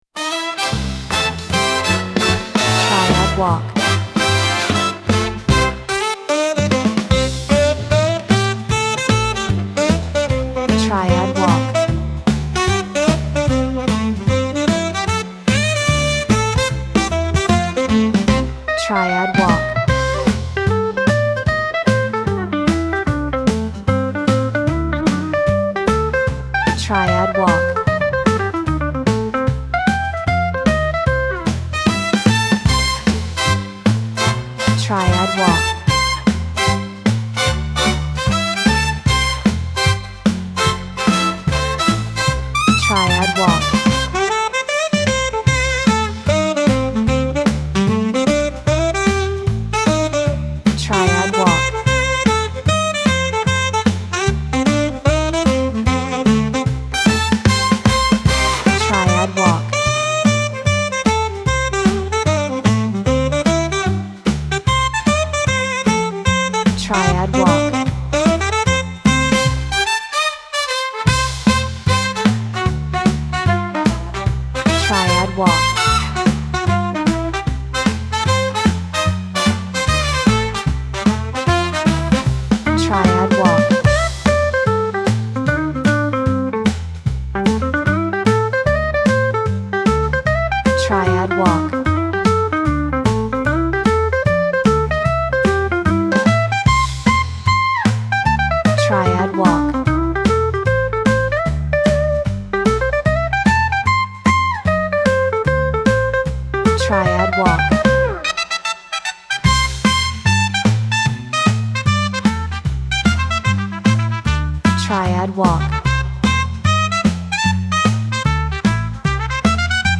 Bass , Drum
Alto Saxophone , Jazz , Trumpet